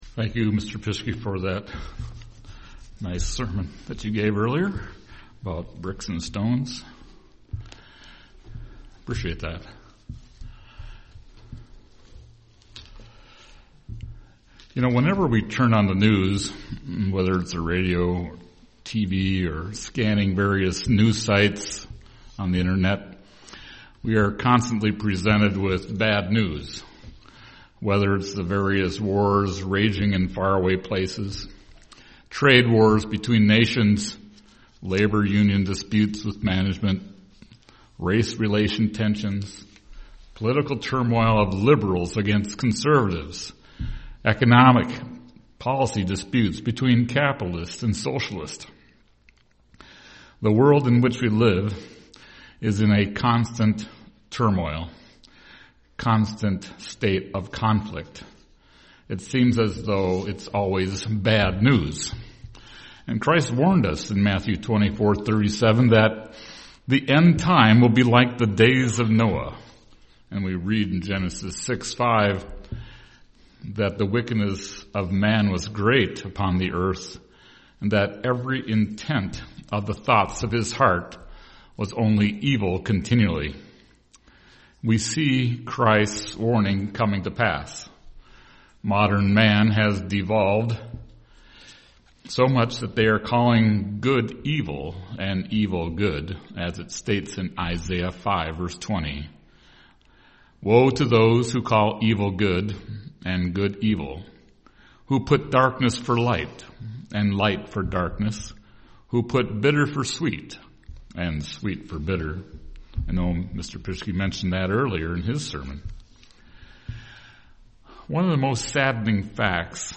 UCG Sermon joy Studying the bible?